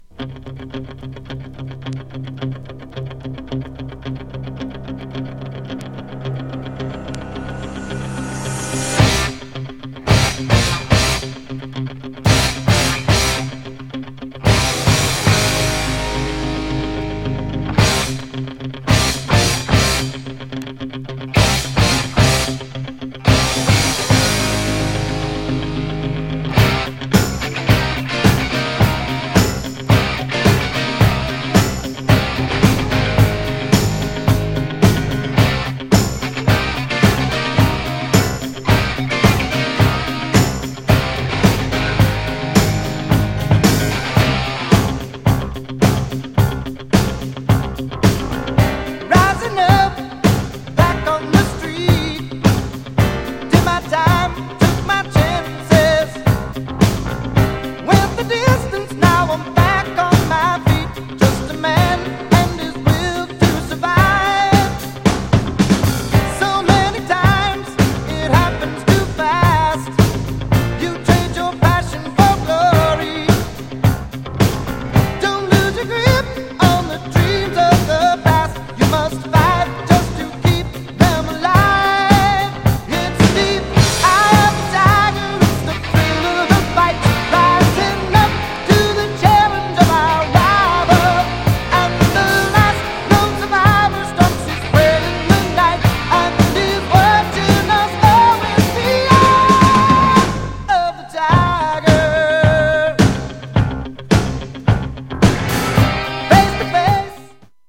GENRE Dance Classic
BPM 106〜110BPM